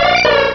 Cri de Lippouti dans Pokémon Rubis et Saphir.